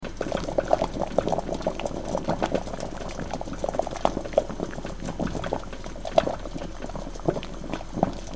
Кипящая вода булькает в кастрюле